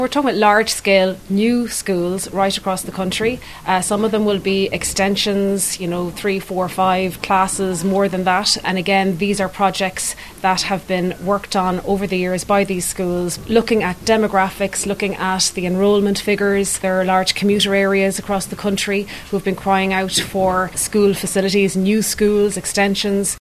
Education Minister Hildegarde Naughton, outlines what some of the new projects will look like……………….